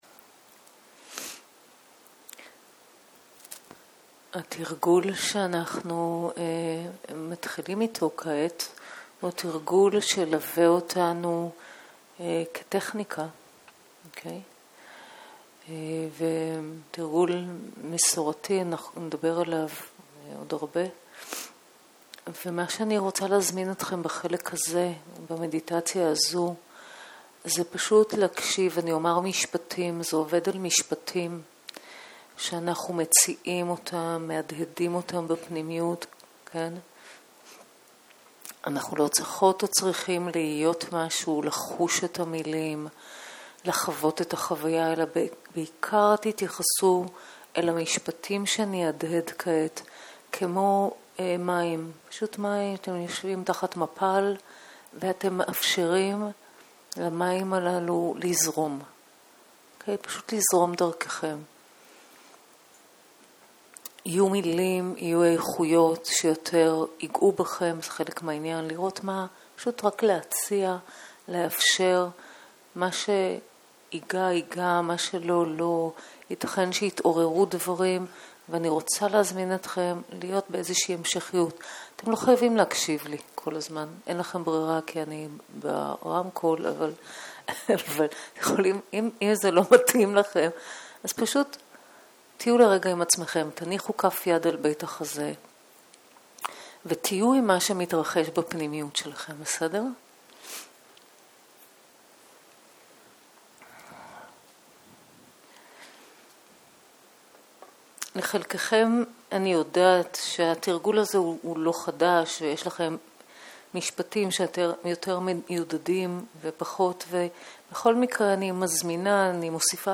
צהרים - מדיטציה מונחית